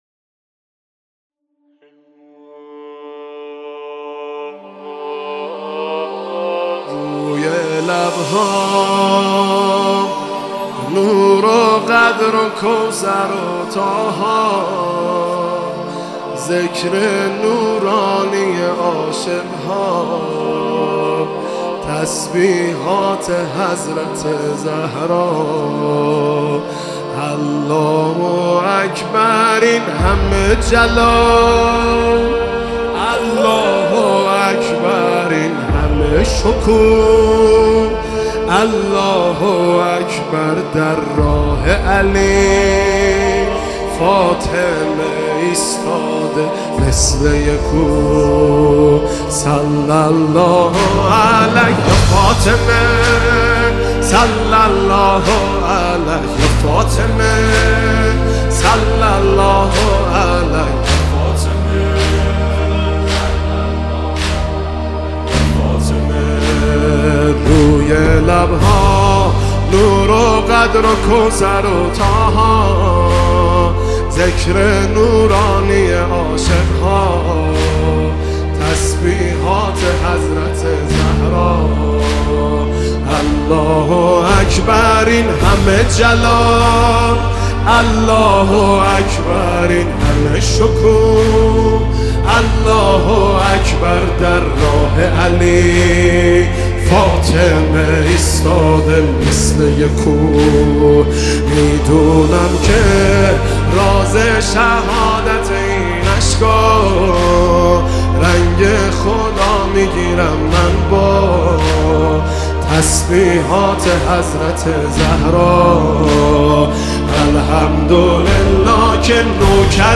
* کیفیت صوتی اصلی و شفاف